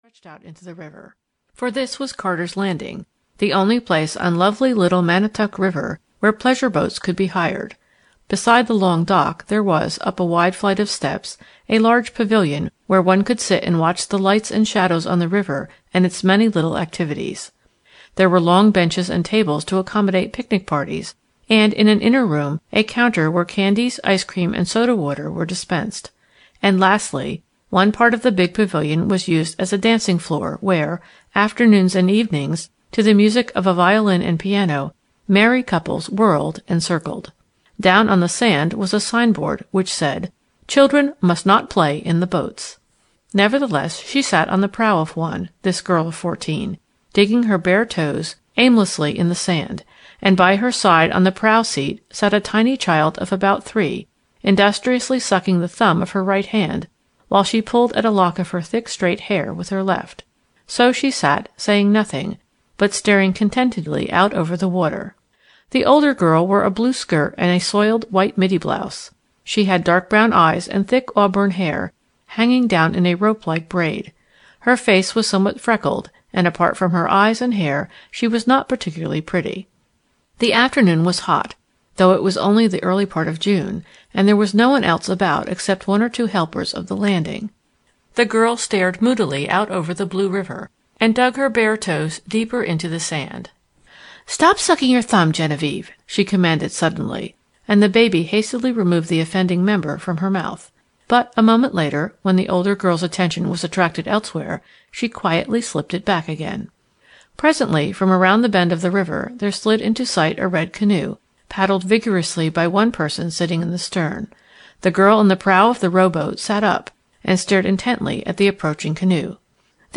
The Slipper-point Mystery (EN) audiokniha
Ukázka z knihy